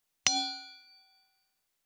308,金属製のワインカップ,ステンレスタンブラー,シャンパングラス,ウィスキーグラス,ヴィンテージ,ステンレス,金物グラス,
効果音厨房/台所/レストラン/kitchen食器